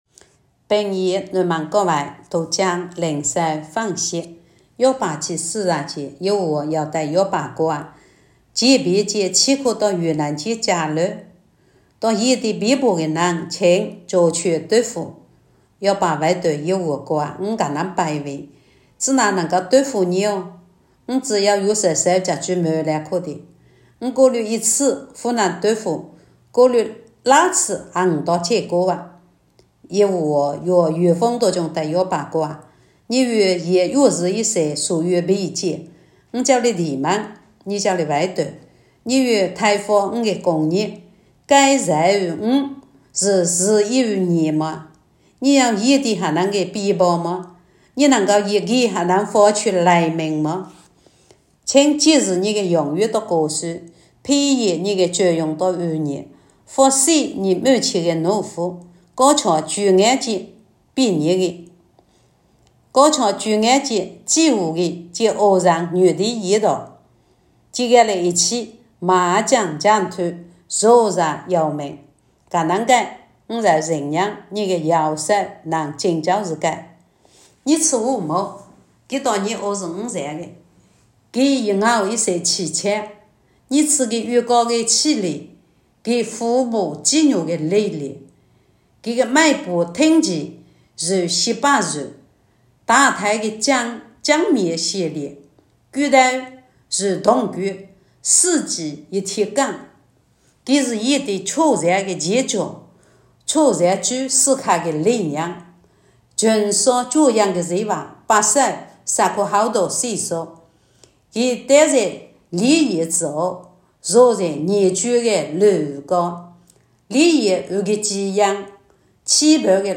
平阳话朗读——伯40
平阳南门教会读经灵修分享（伯40）.m4a